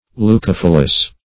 Search Result for " leucophyllous" : The Collaborative International Dictionary of English v.0.48: Leucophyllous \Leu*coph"yl*lous\ (l[-u]*k[o^]f"[i^]l*l[u^]s or l[=u]`k[-o]*f[i^]l"l[u^]s), a. [Gr. leyko`fyllos; leyko`s white + fy`llon a leaf.]
leucophyllous.mp3